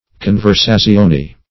Search Result for " conversazione" : The Collaborative International Dictionary of English v.0.48: Conversazione \Con`ver*sa`zi*o"ne\ (? or ?), n.; pl. Conversazioni .